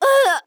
YX死亡1.wav 0:00.00 0:00.47 YX死亡1.wav WAV · 44 KB · 單聲道 (1ch) 下载文件 本站所有音效均采用 CC0 授权 ，可免费用于商业与个人项目，无需署名。
人声采集素材